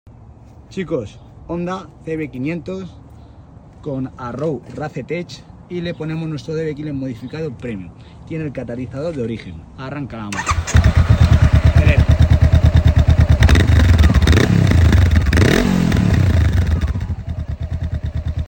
🔥 Hoy rugió en el garaje una Honda CB500F con escape Arrow Race Tech 🏍. El objetivo era claro: más sonido y más petardeo 💥🔊.
👇 ⸻ 🔥 Today at the garage we had a Honda CB500F with an Arrow Race Tech exhaust 🏍.
🔊 A sharper roar 💥 Pops that make you grin 😎 And a CB500F that now sounds as good as it looks.